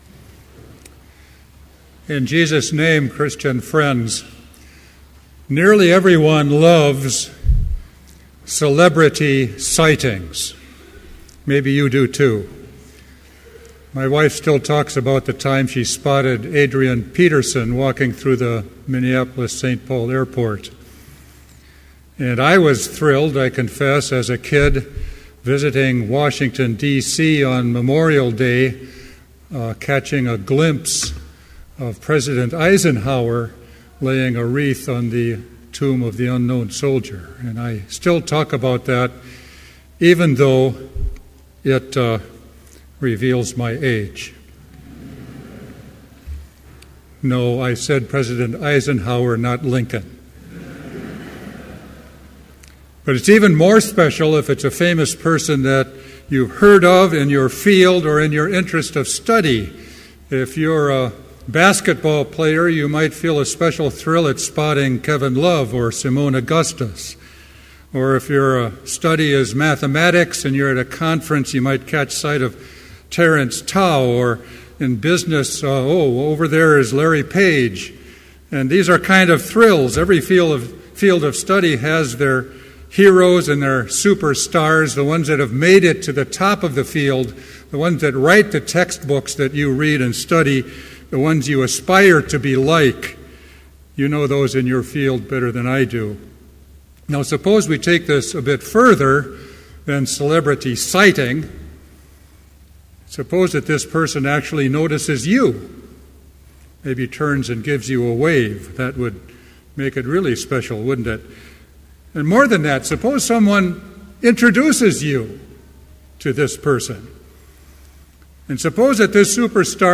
Complete Service
• Prelude
• Homily
This Chapel Service was held in Trinity Chapel at Bethany Lutheran College on Thursday, December 5, 2013, at 10 a.m. Page and hymn numbers are from the Evangelical Lutheran Hymnary.